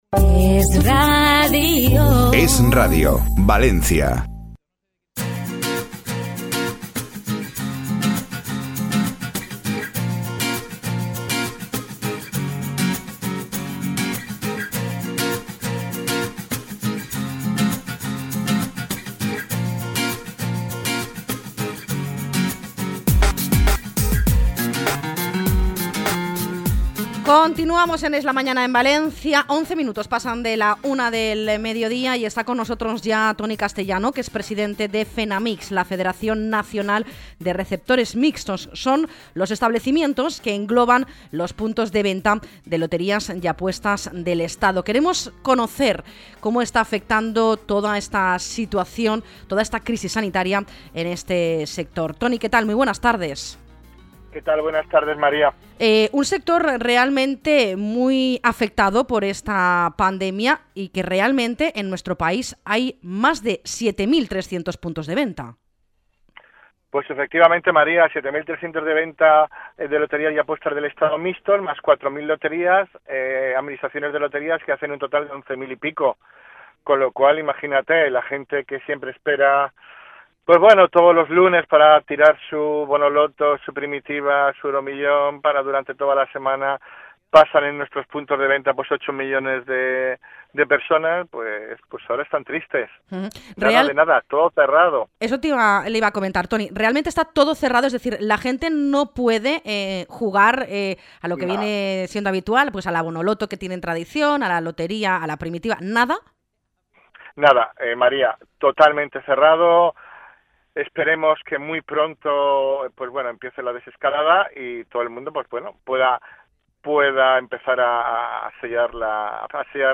entrevista-esradio.mp3